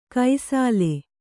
♪ kai sāle